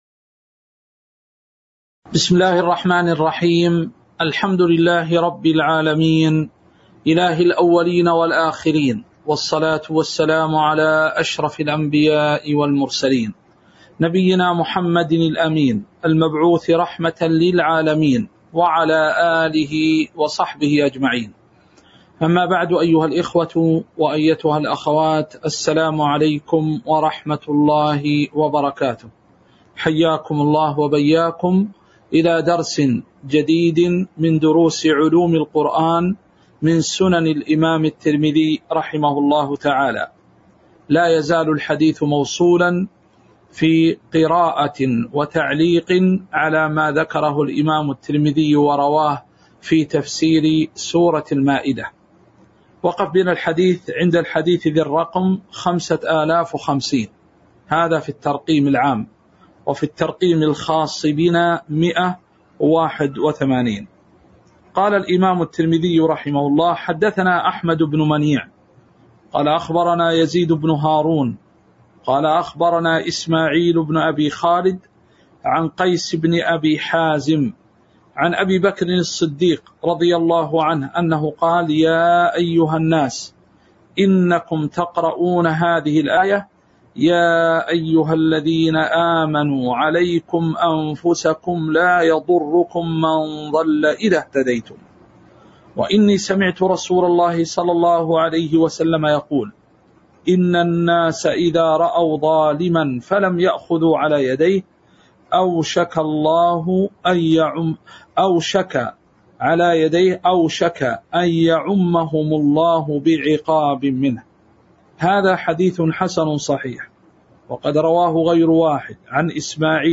تاريخ النشر ١ ربيع الثاني ١٤٤٣ هـ المكان: المسجد النبوي الشيخ